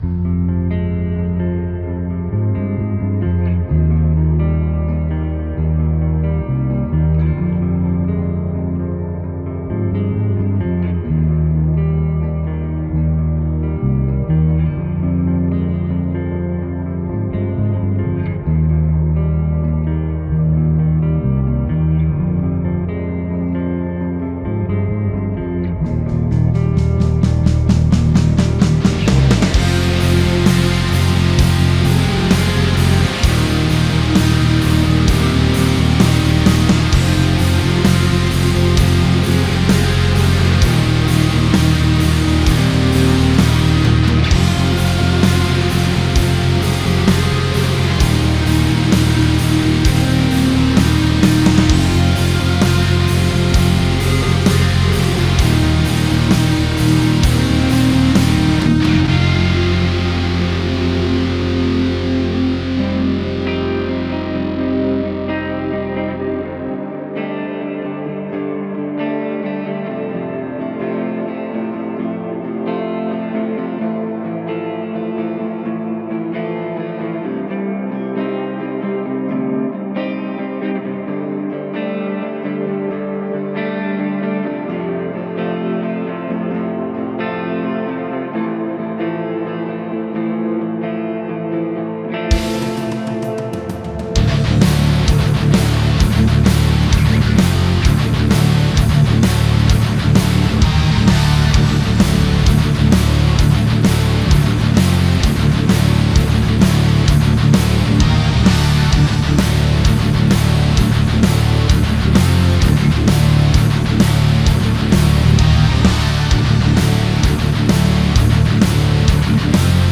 Servus, Ich würde gerne mal eure Meinung zu meinem ersten, ernsthaften Mix hören.
Bei den Drums vermisse ich die Transienten, dass die so richtig knallen. Find ich sehr wichtig bei Metal, ebenso vertragen Metaldrums viiiiiiiele Höhen und viiiiiel Raum Weiters finde ich wie meine Vorredner auch, die Cleangitarre im Intro, und auch später, da ist viel zu viel Hall drauf.
Wo neben den Rhytmusgitarren L+R noch die Sologitarre in der Mitte spielt, wirds mMn etwas überladen, bzw die Sologitarre bekommt nicht die Aufmerksamkeit die sie bräuchte.
Den Anshlag hör ich aber kein Body untenrum.